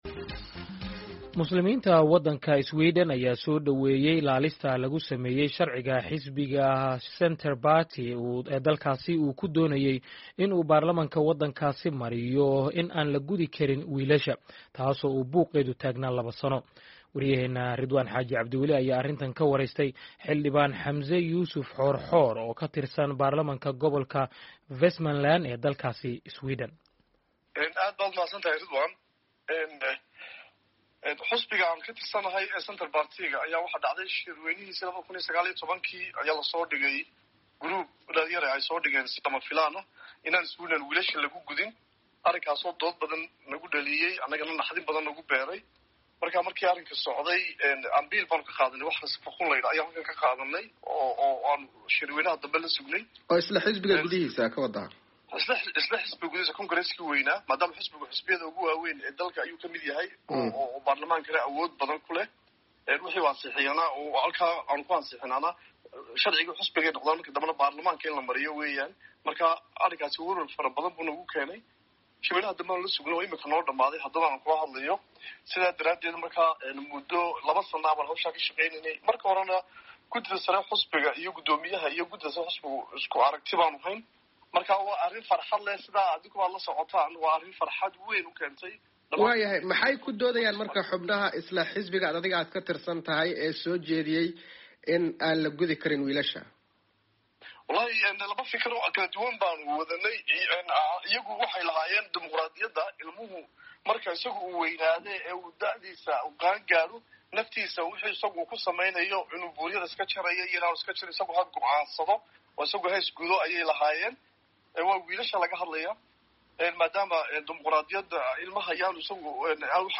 arrintan ka waraystay xildhibaan Xamse Yusuf Xoor-Xoor